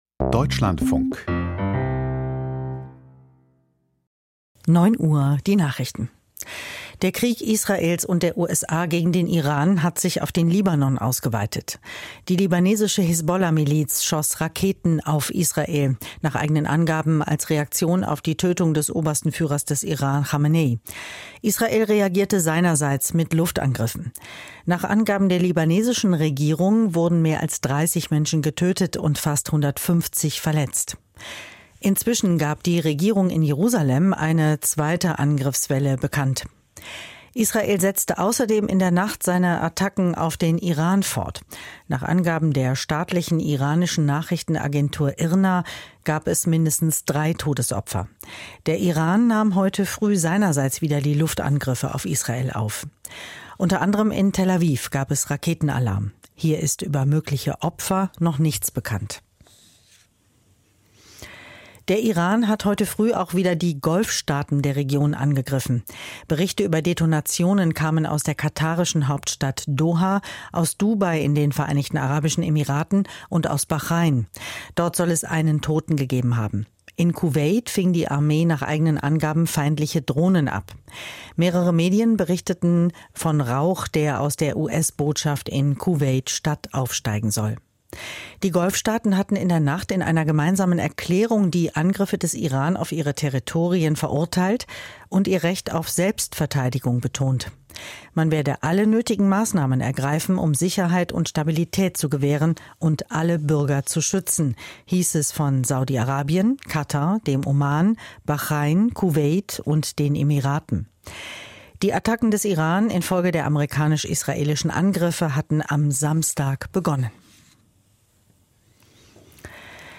Die Nachrichten vom 02.03.2026, 09:00 Uhr
Aus der Deutschlandfunk-Nachrichtenredaktion.